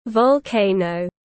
Volcano /vɒlˈkeɪ.nəʊ/